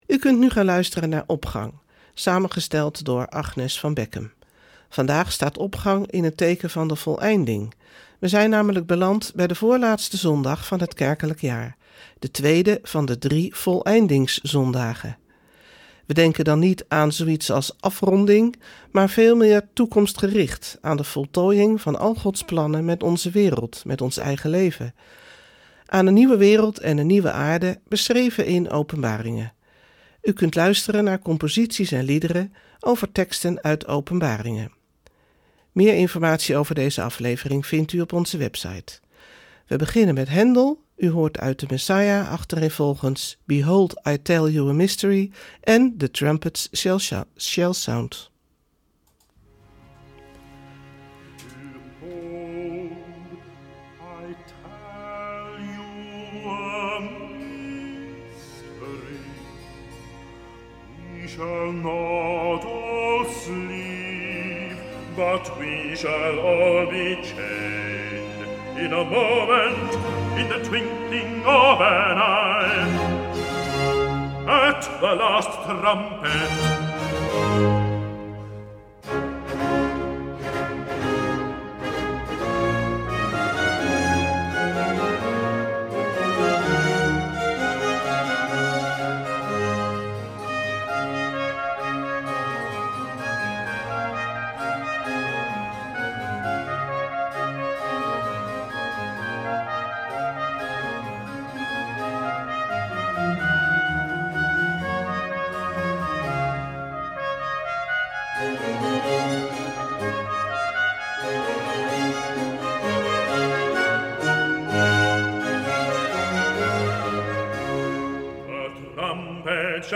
Opening van deze zondag met muziek, rechtstreeks vanuit onze studio.
U kunt luisteren naar composities en liederen over teksten uit Openbaringen.